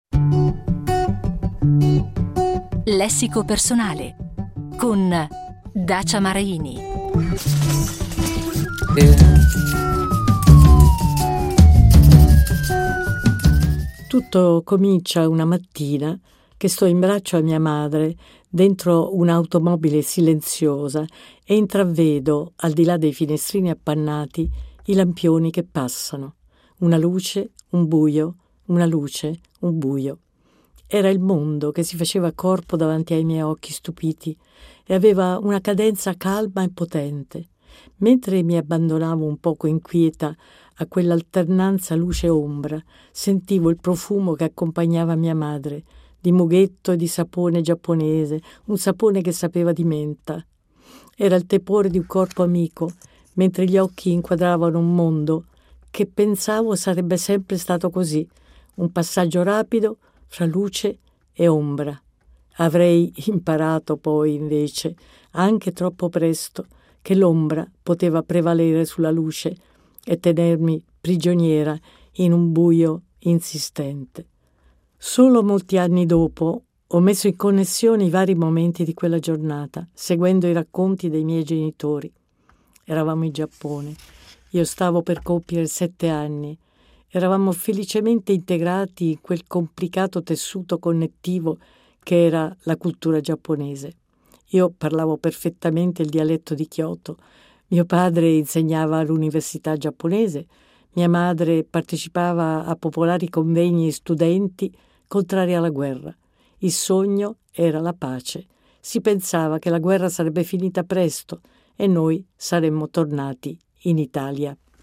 Ogni giorno, da lunedì a venerdì, Dacia Maraini, in piccoli schizzi narrativi ripresi dal libro, ci accompagnerà dentro le memorie di lei bambina prigioniera.